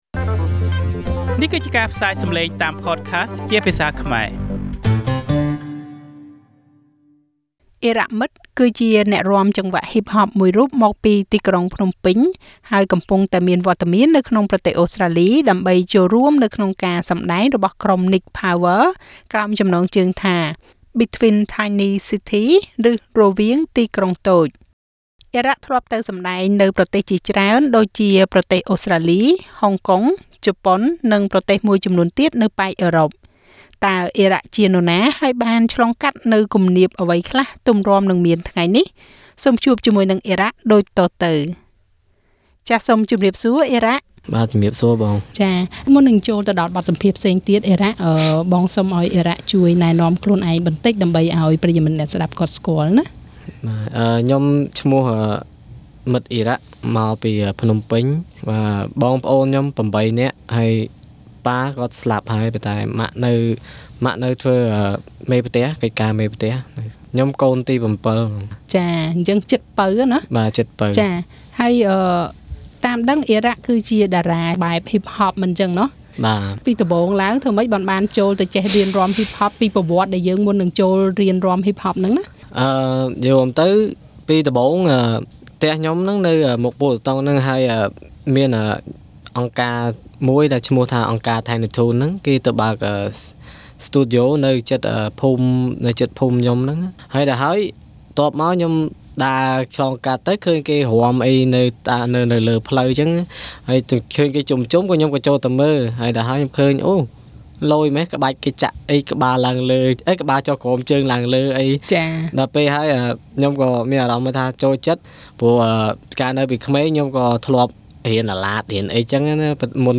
interview with SBS Khmer